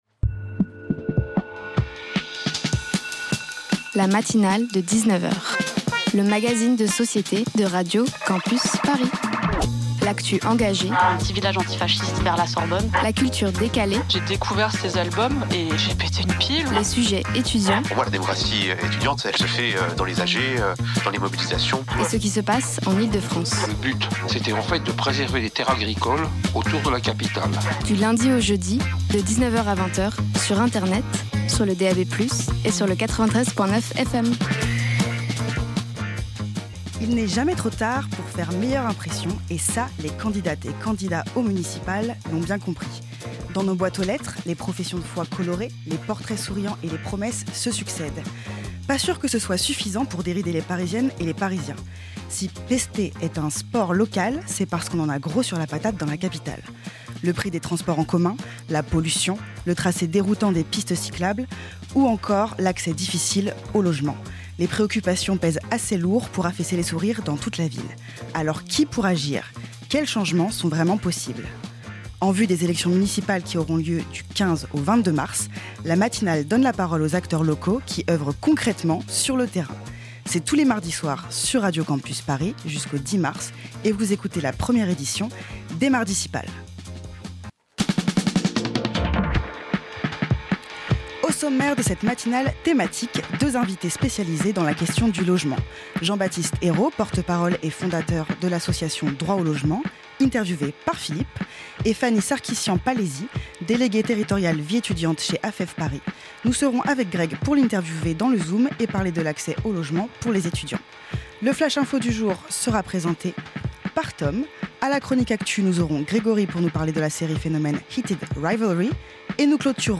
À quelques semaines du vote pour les élections municipales, La Matinale de 19h fait son émission spéciale : les "Mardicipales" !
Chacune de ces émissions sera l'occasion d'aborder une thématique forte de la campagne municipales, en compagnie d'acteurs locaux, de la vie culturelle et sociale.